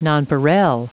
Prononciation du mot nonpareil en anglais (fichier audio)
Prononciation du mot : nonpareil